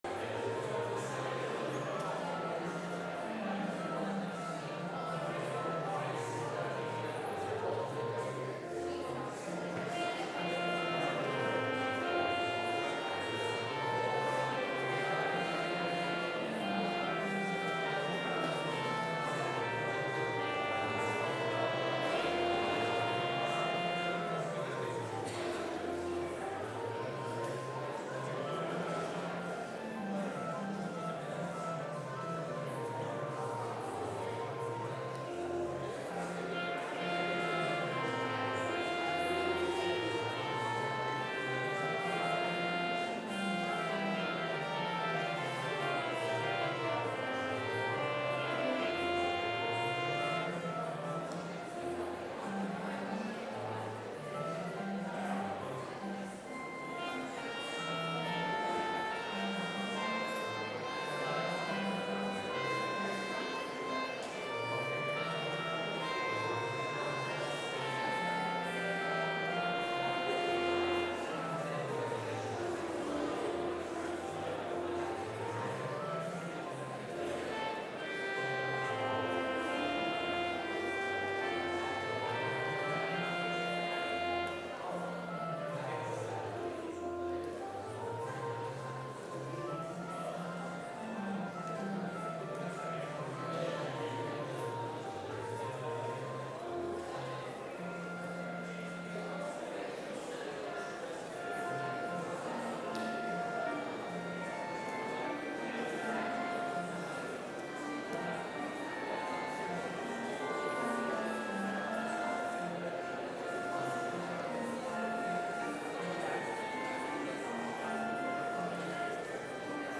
Complete service audio for Chapel - Wednesday, October 25, 2023
Commentary between hymn verses Hymn 378 - Dear Christians, One and All, Rejoice